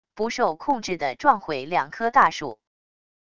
不受控制的撞毁两颗大树wav音频